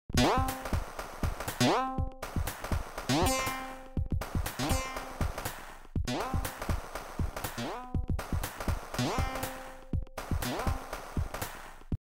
ALL Sonic Characters Jumping on sound effects free download
ALL Sonic Characters Jumping on a Spring